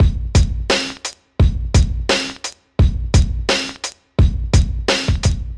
沉重的帽子鼓声循环
标签： 86 bpm Hip Hop Loops Drum Loops 961.53 KB wav Key : Unknown
声道立体声